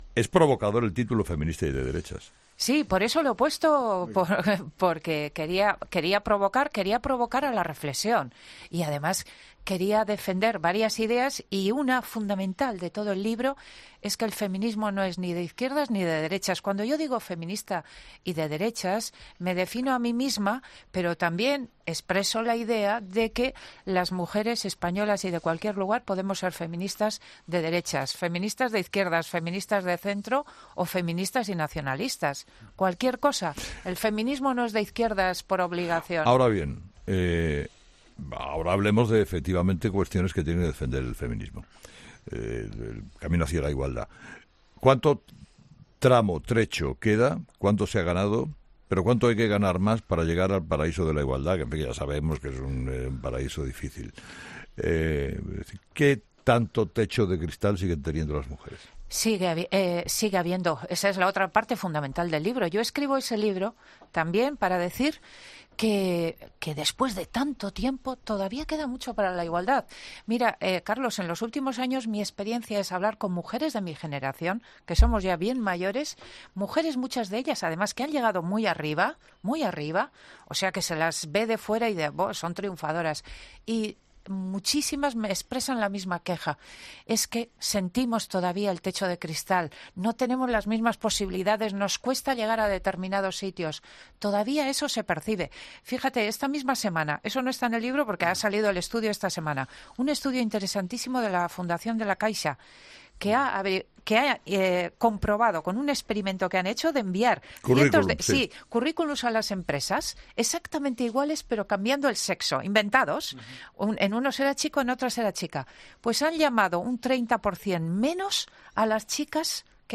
Entrevista a Edurne Uriarte COPE